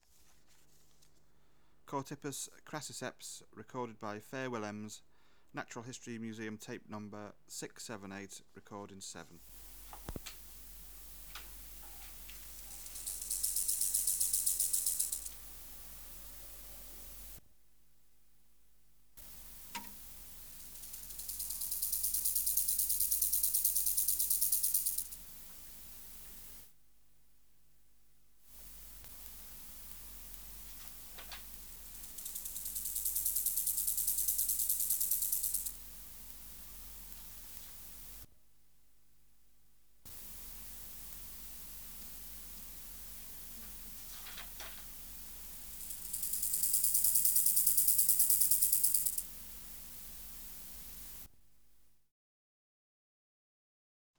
Natural History Museum Sound Archive Species: Chorthippus (Glyptobothrus) crassiceps